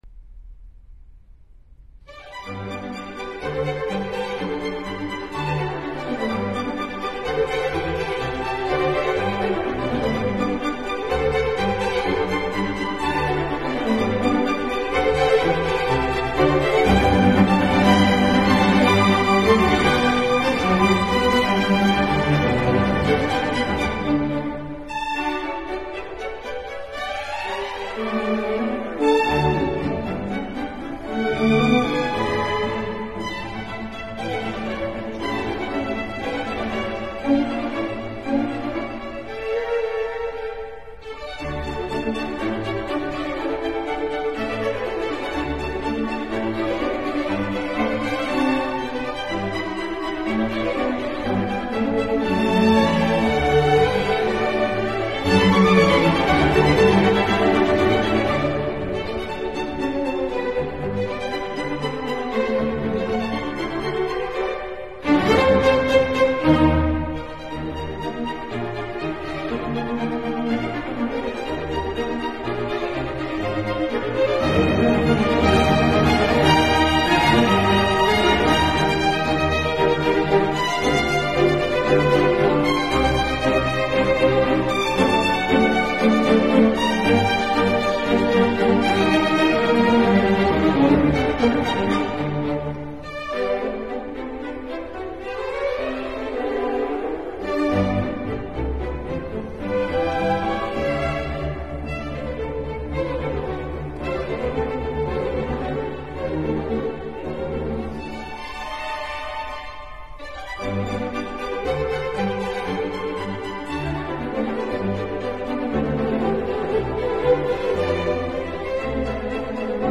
about Music Sundowner Programme 19 Serenades and Nocturnes Today’s programme is all about relaxation. Serenades and music suitable for the night.